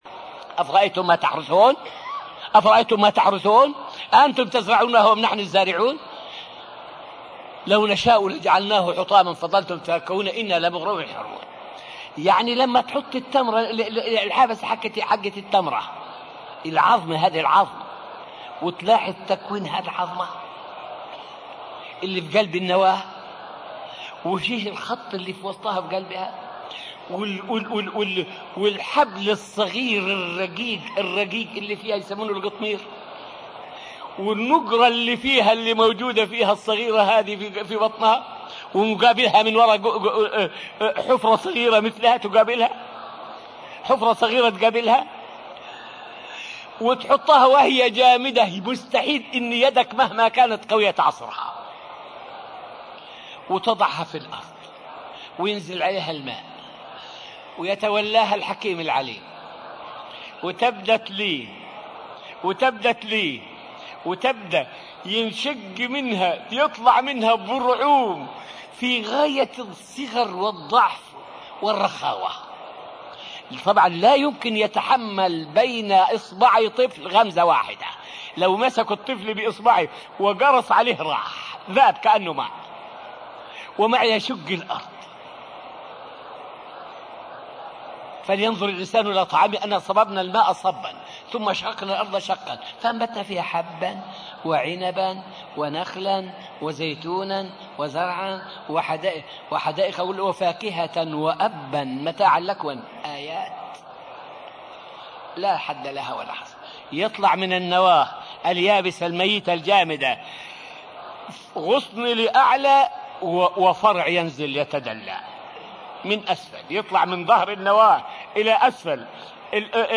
فائدة من الدرس الثاني والعشرون من دروس تفسير سورة البقرة والتي ألقيت في المسجد النبوي الشريف حول من الآيات الدالة على الله.